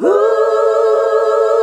HUH SET A.wav